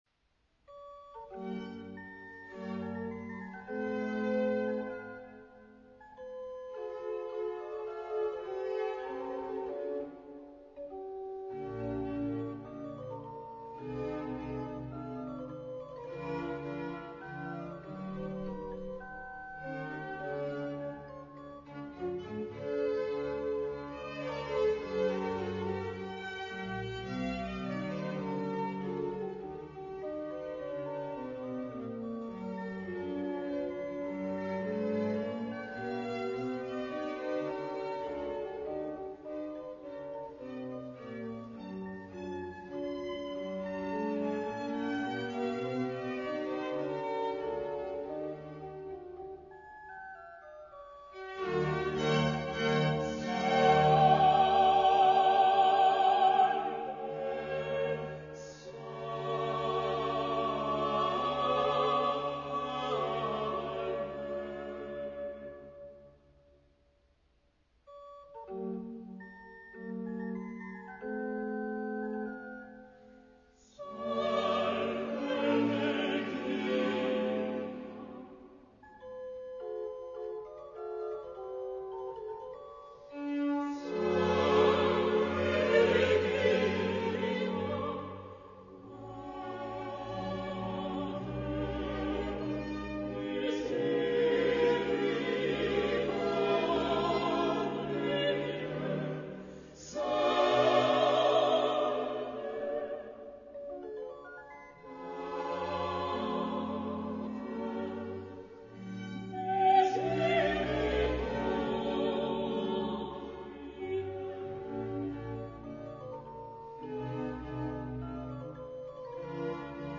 J. Haydn: Salve Regina g-moll Hob.XXIIIb:2 Adagio [.mp3]